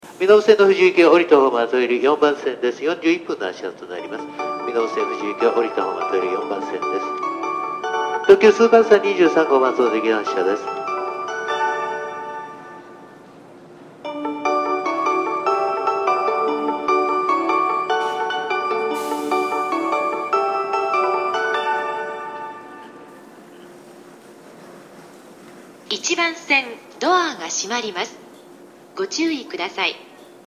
発車メロディー２コーラスです。特急電車はフルコーラス以上は高確率で鳴りやすいです。